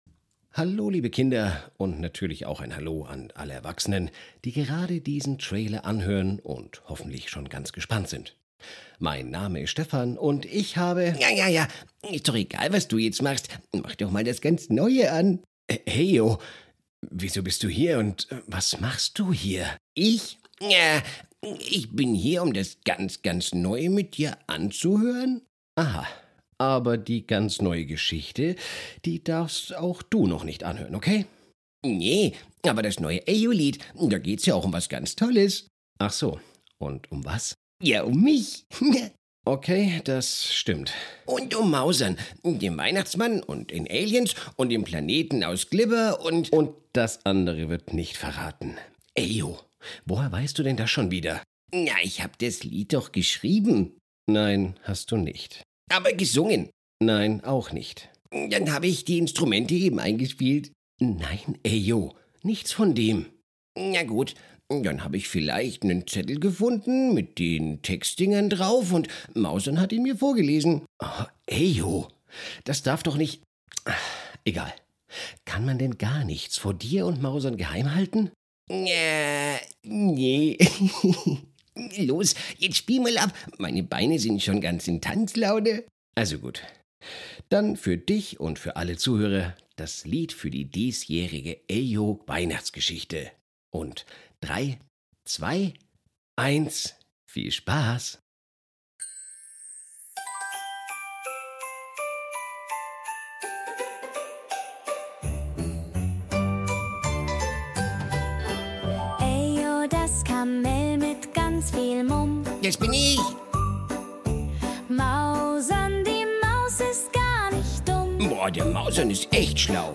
schonmal der ganz neue Titelsong für die neue